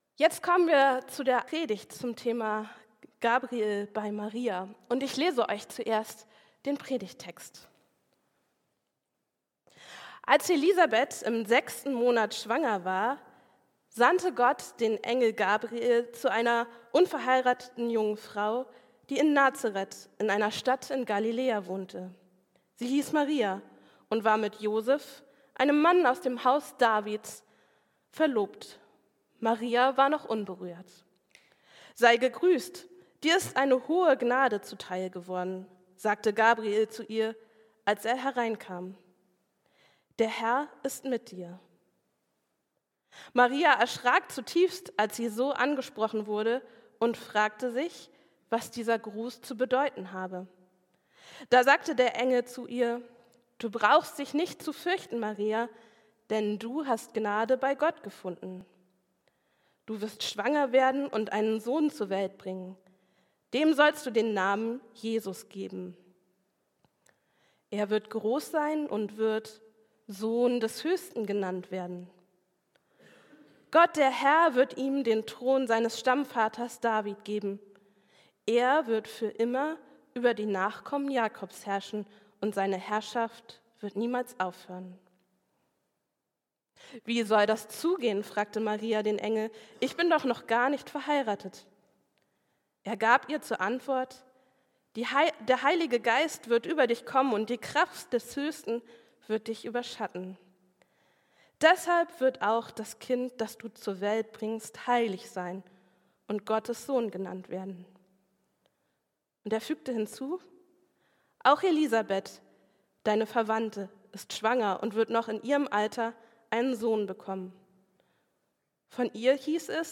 Engel (2/3): Marias Doppelschock ~ Christuskirche Uetersen Predigt-Podcast Podcast